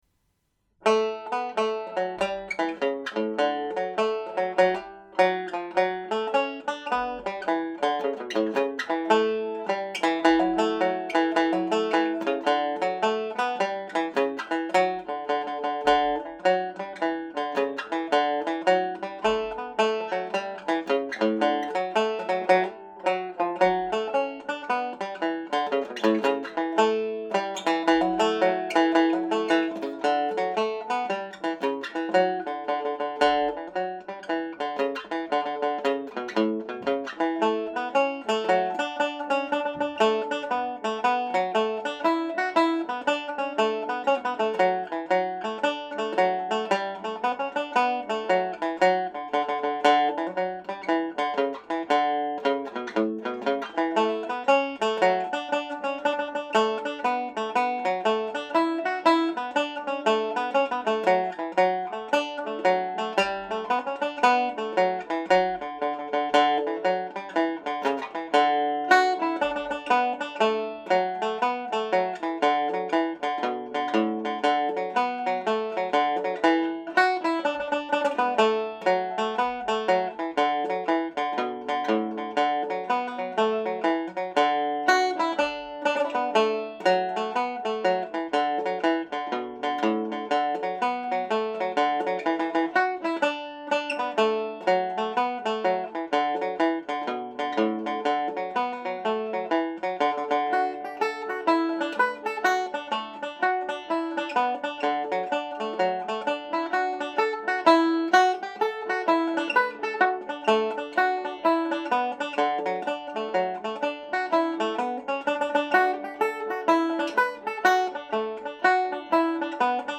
Soporific hornpipe and Kitty’s Wedding played as a set